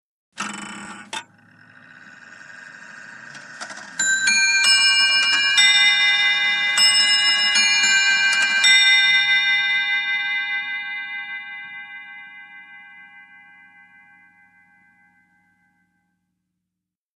CLOCKS ANTIQUE CLOCK: INT: Antique clock bell chimes half hour.